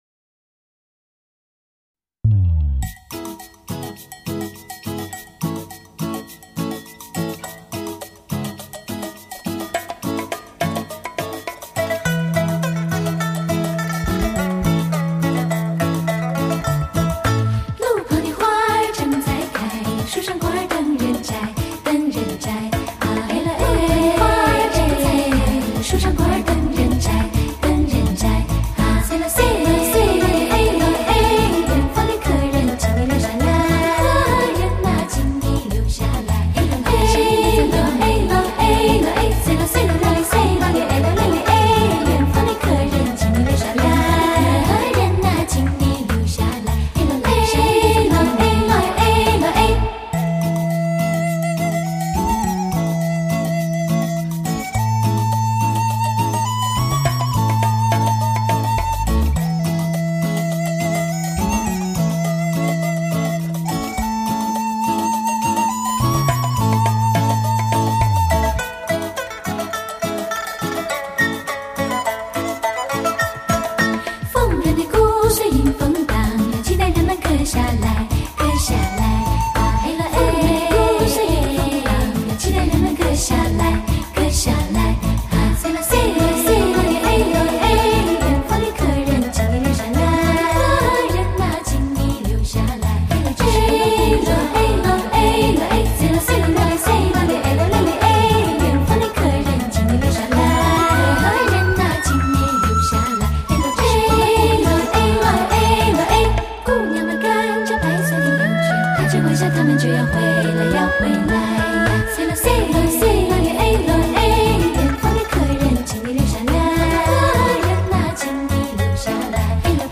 一群年轻美丽的姑娘
这次录制比上一次更超越的地方就是每个队员用一个专声道来录制，光是人声部分的录制已多达24声轨
撒尼族民歌